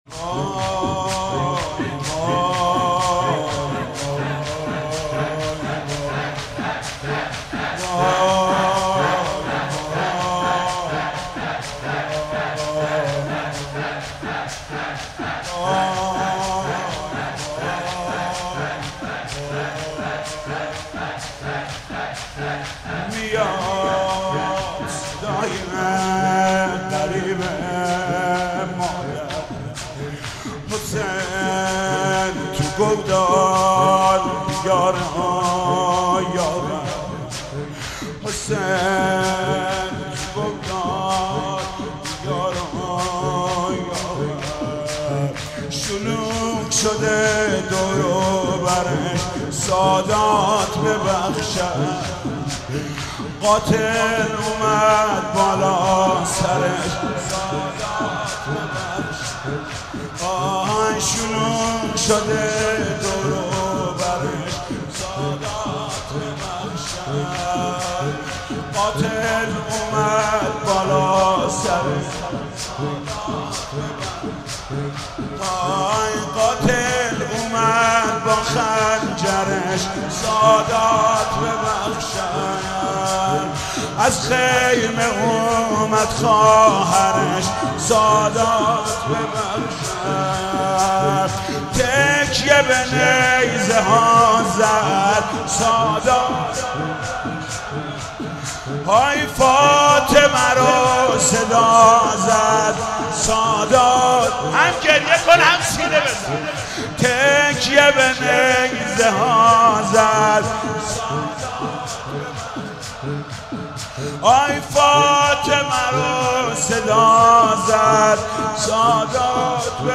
زمینه: قاتل اومد بالا سرش سادات ببخشند